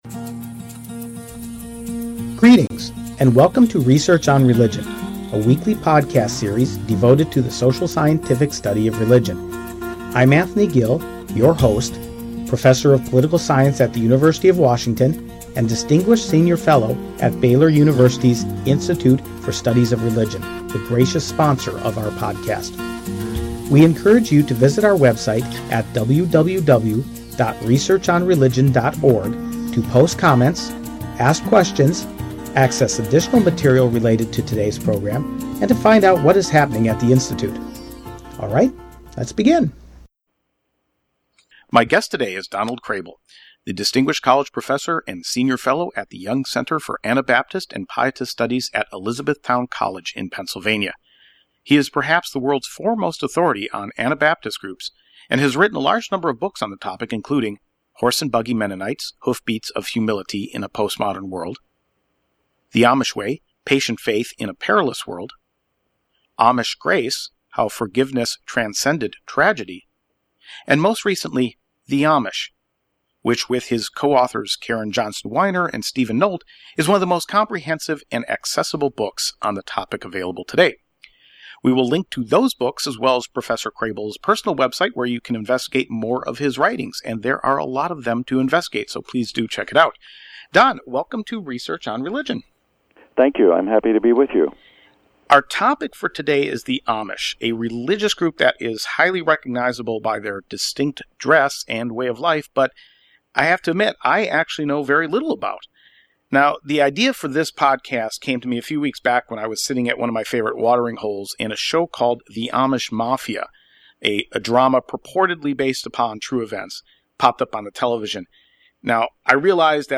The conversation also includes various references to Mennonites, both the modern, mainstream version and the “horse and buggy” (or Old Order) Mennonite community.